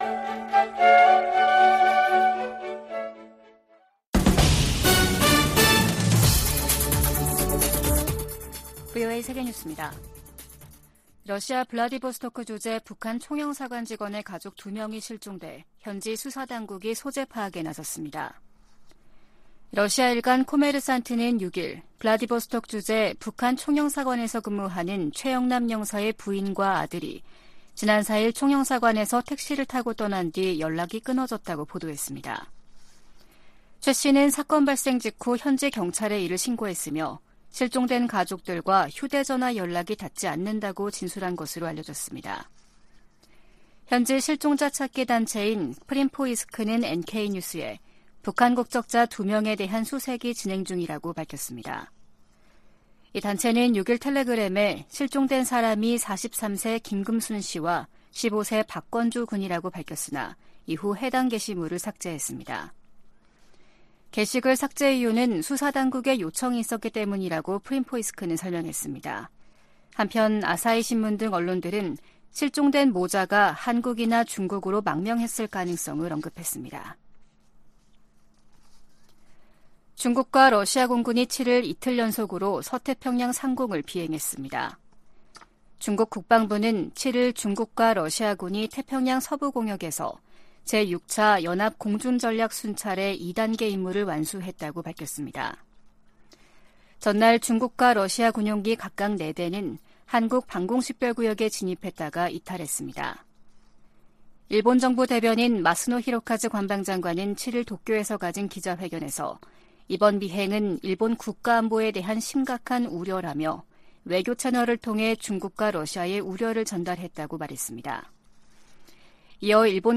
VOA 한국어 아침 뉴스 프로그램 '워싱턴 뉴스 광장' 2023년 6월 8일 방송입니다. 한국이 유엔 안전보장이사회 비상임이사국으로 다시 선출됐습니다.